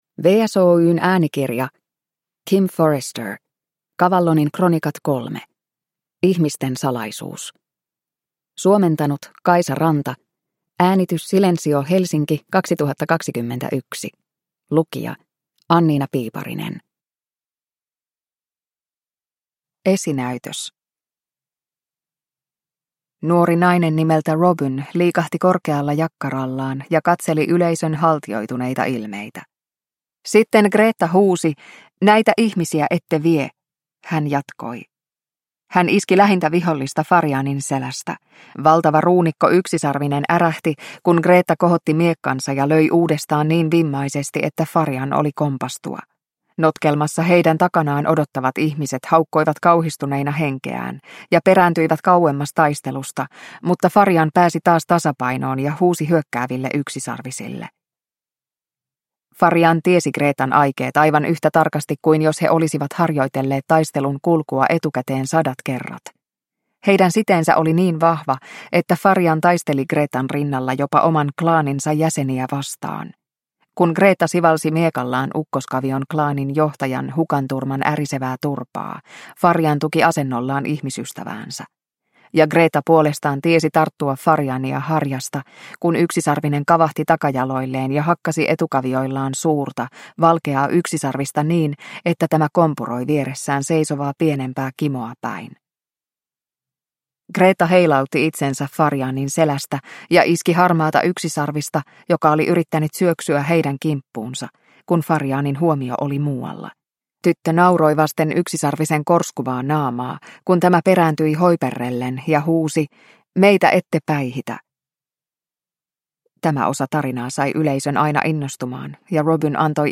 Cavallonin kronikat 3: Ihmisten salaisuus – Ljudbok – Laddas ner